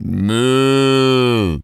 pgs/Assets/Audio/Animal_Impersonations/cow_moo_03.wav at master
cow_moo_03.wav